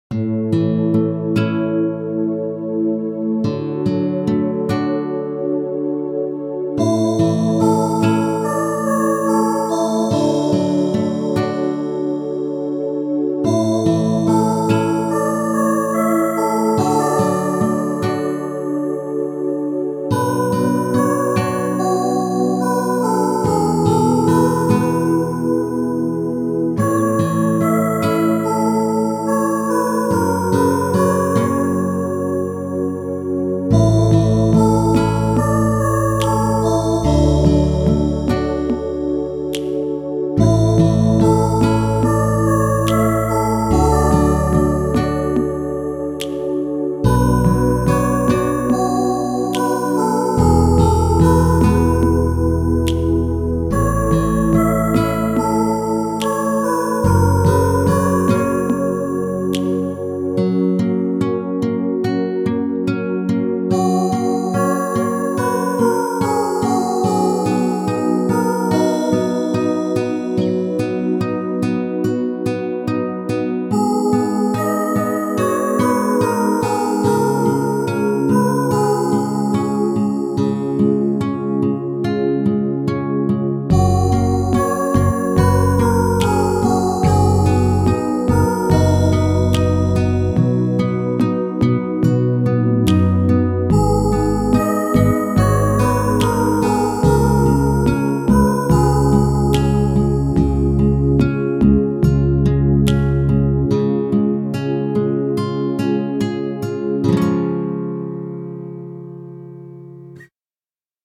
イメージ：なだらか 癒し   カテゴリ：明るい・日常